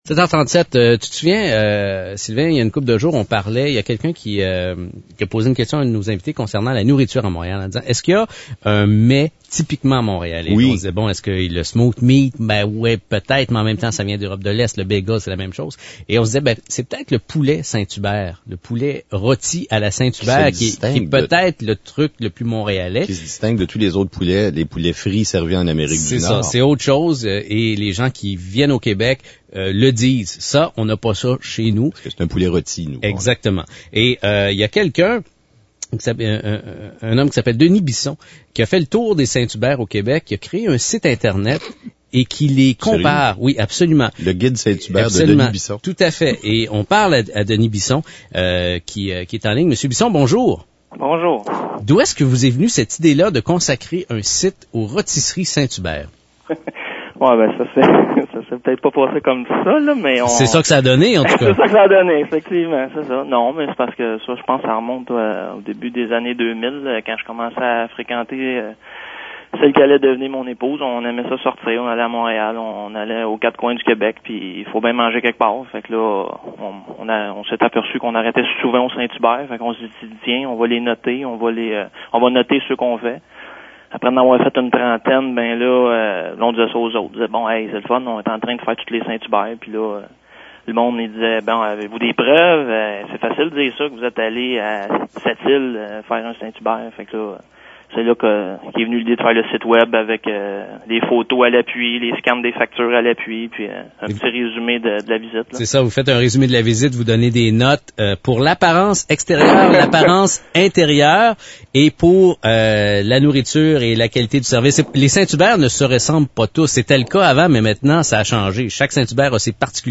Entrevue radiophonique donnée à André Ducharme à "Puisqu'il faut se lever" au 98.5FM.